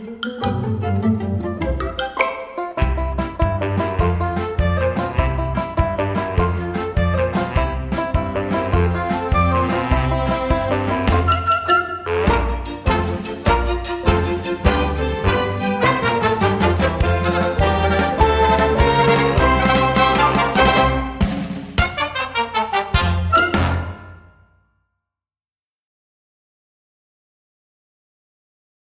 • September 3rd – In rehearsal the kids will all learn a group dance and a short singing solo (the “audition cut”, shared below.)